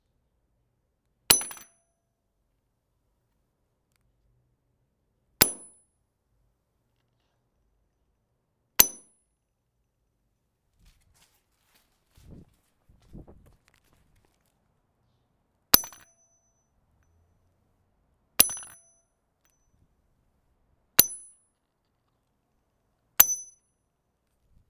metal hits concrete
break cement concrete ding hit impact metal strike sound effect free sound royalty free Sound Effects